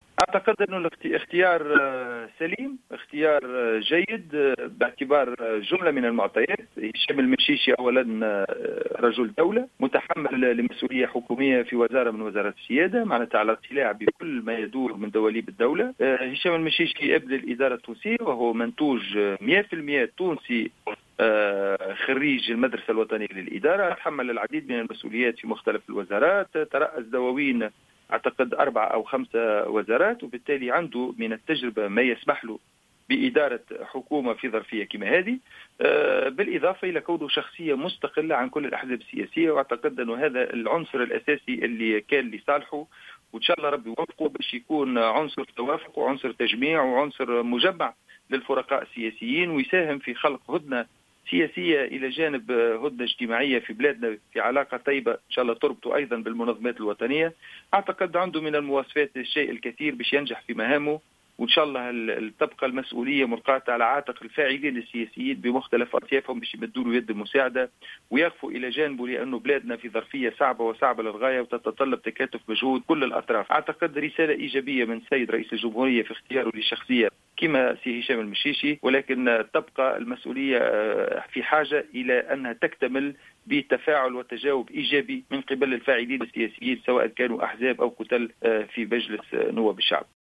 اعتبر رئيس كتلة الاصلاح حسونة الناصفي ان تكليف رئيس الجمهورية هشام المشيشي بتشكيل حكومة، اختيارا سليما وجيدا باعتبار جملة من المعطيات تتمثل في كونه رجل دولة وعلى اطلاع بكل ما يدور في دواليب دولة كما أنه ابن الإدارة التونسية، وفق تعبيره في تصريح لـ "الجوهرة أف ام".